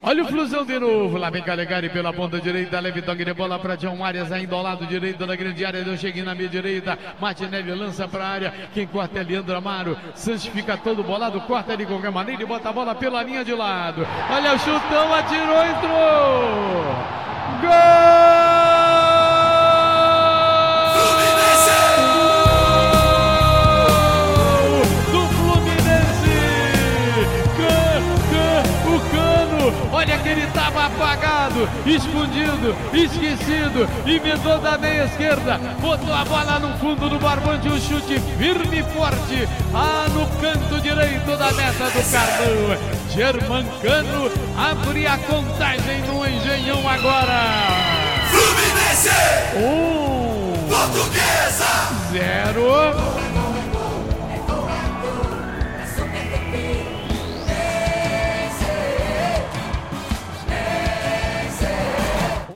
Ouça o gol da vitória do Fluminense sobre a Portuguesa pelo Carioca com a narração do Garotinho